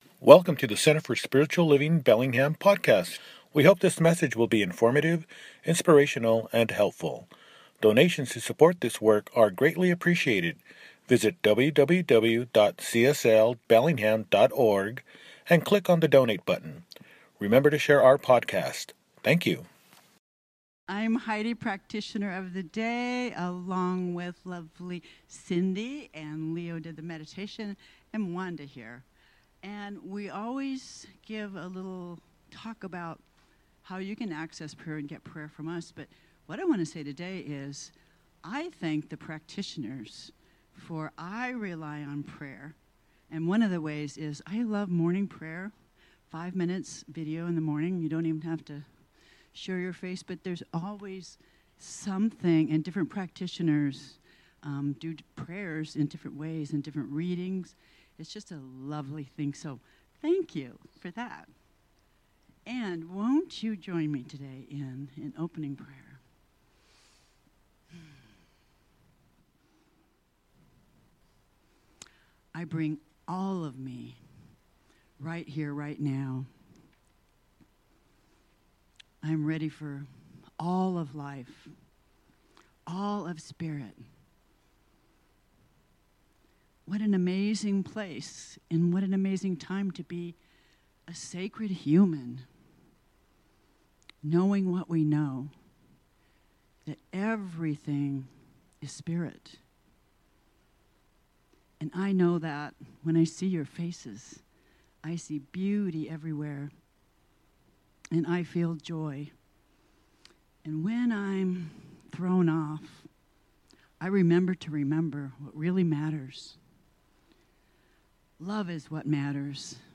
How to Live Your Best Life – Celebration Service